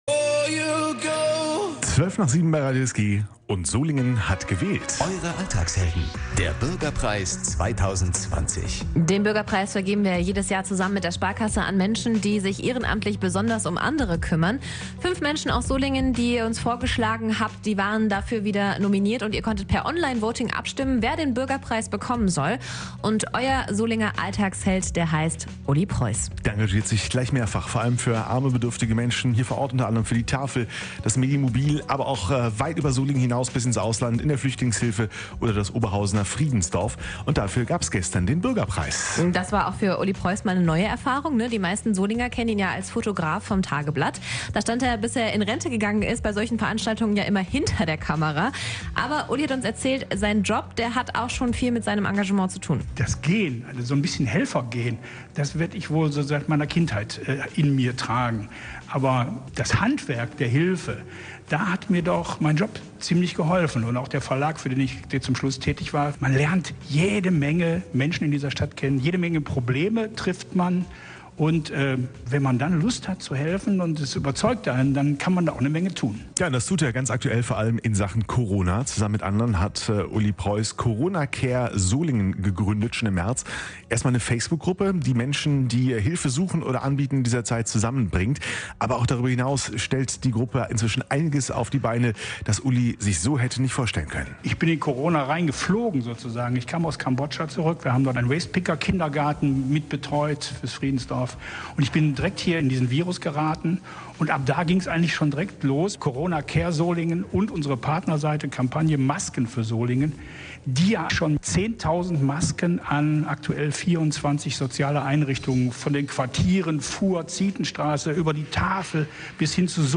Was ihn antreibt und wie sein Helfer-Alltag aussieht, hat er uns in der Morgenshow erzählt.